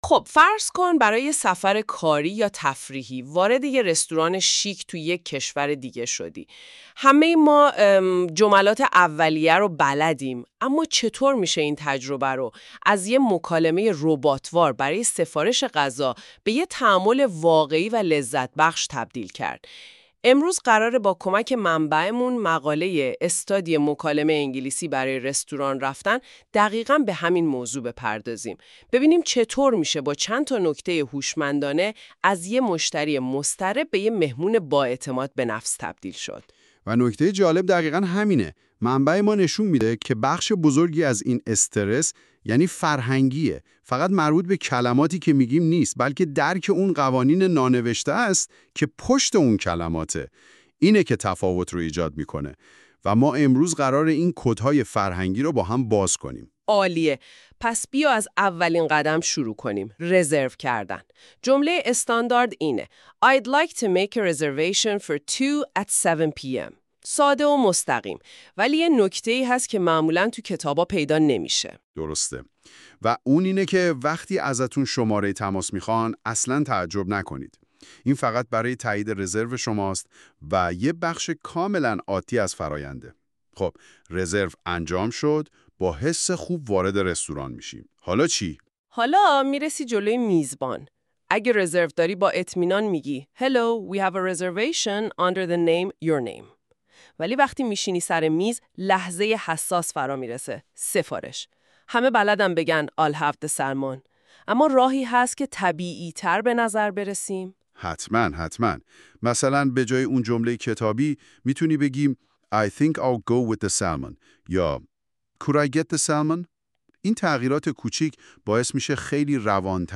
English-Conversation-at-a-Restaurant.mp3